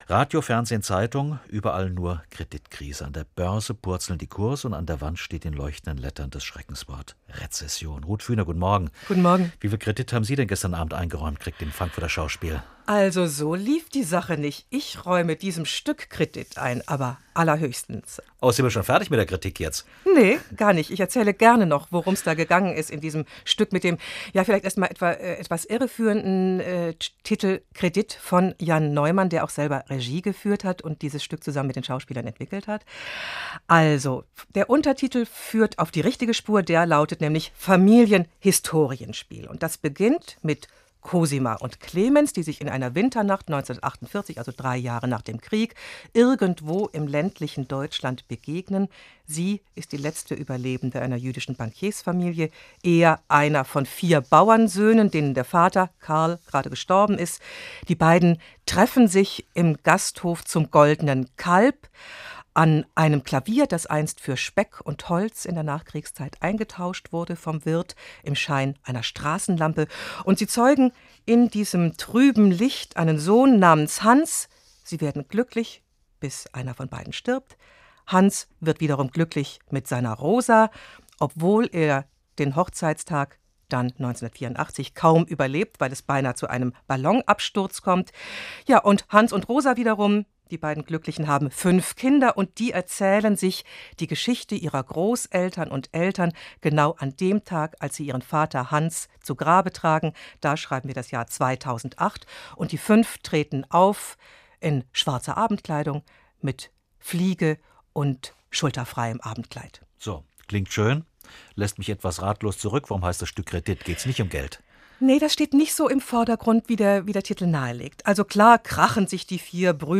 hr2-kultur-Frühkritik am 19.01.2008 [mp3] [7.758 KB] />